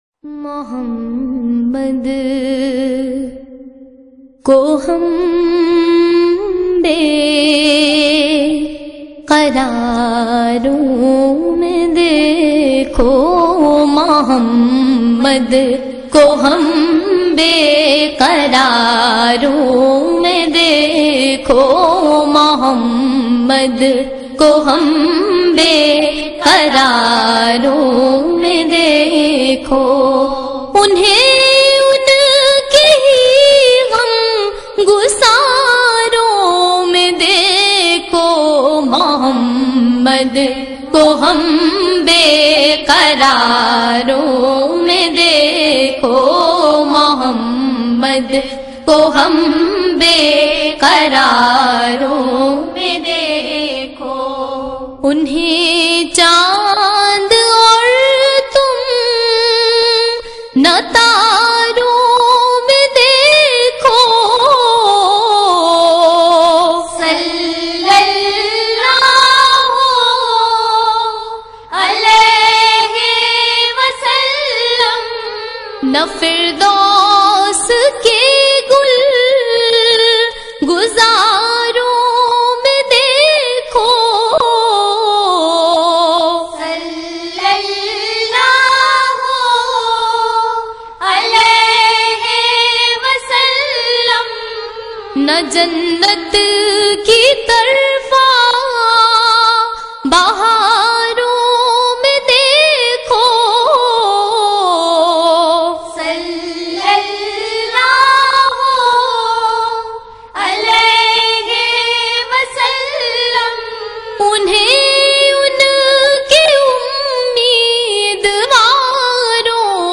naat
Heart-Touching Voice